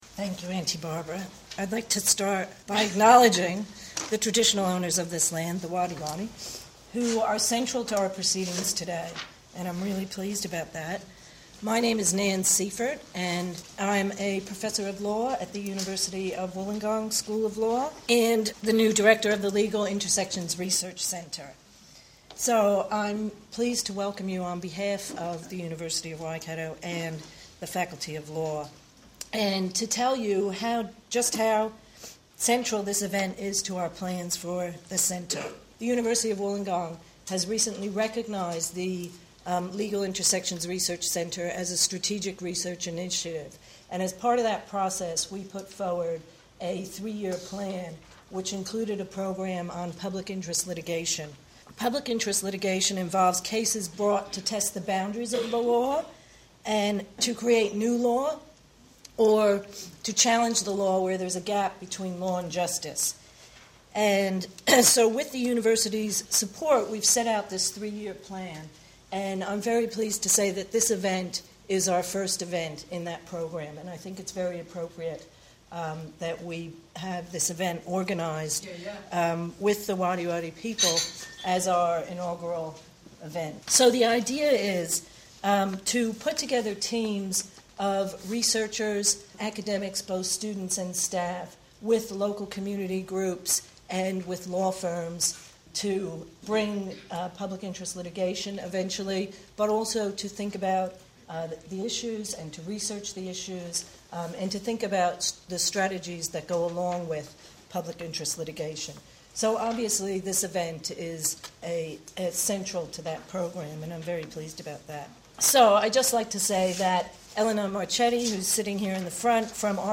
at the 2012 Sovereignty Symposium - Wollongong, New South Wales, May 2012